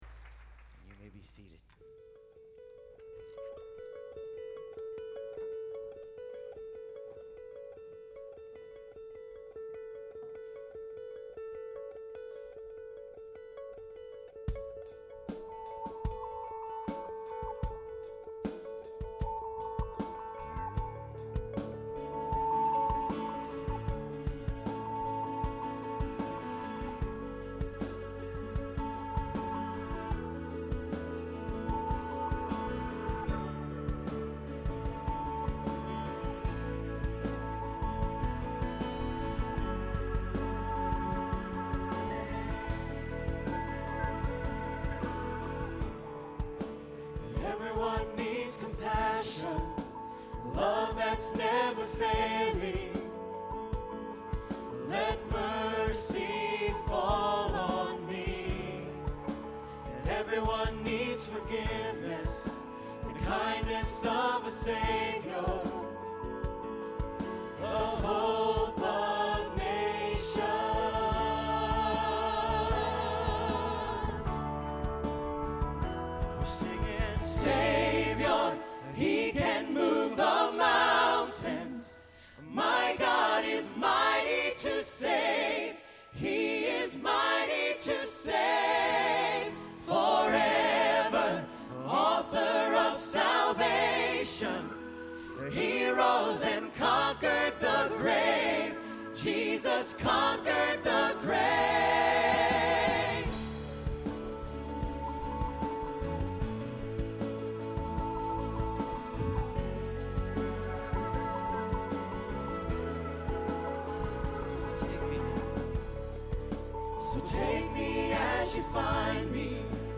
On June 8 we introduced this song to our congregation.
Here is the audio of us doing “Mighty To Save” on June 8 with our team at FCN.
Hammond
(2) When the team sings the chorus after the first bridge – awesome.